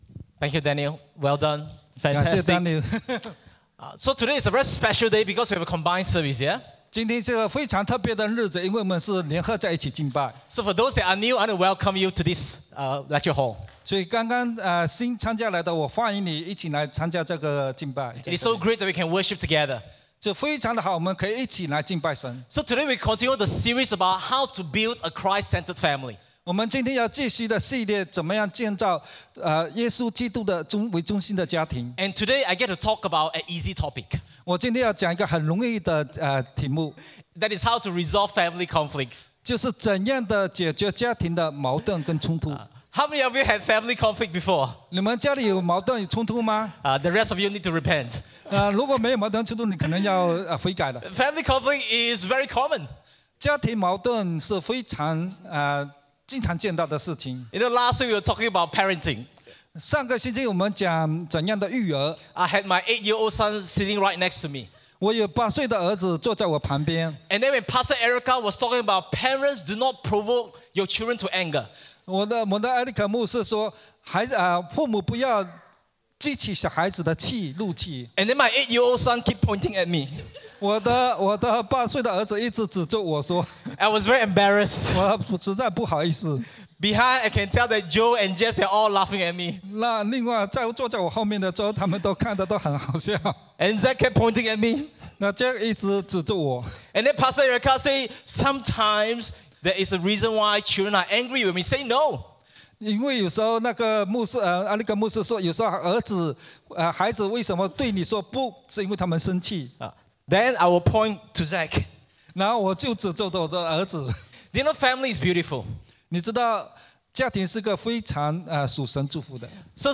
Bilingual Worship Service - 20th November 2022